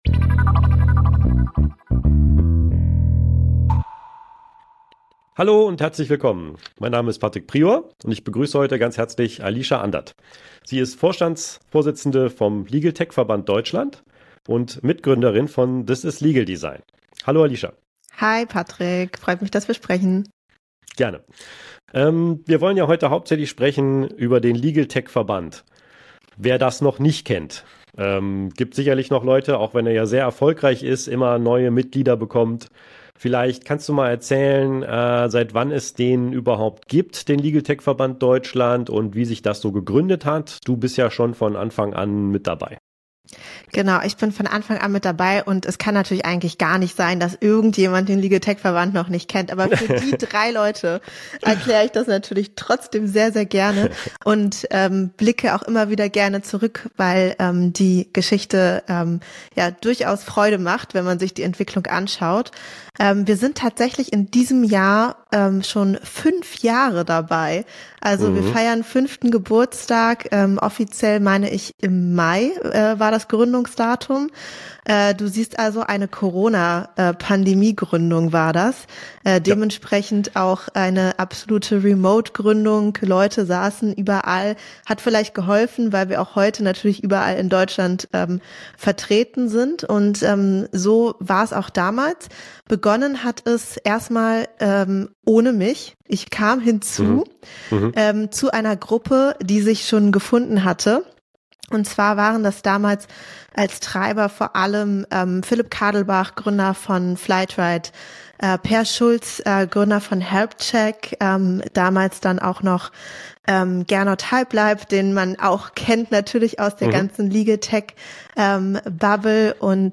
Legal Tech Verzeichnis Podcast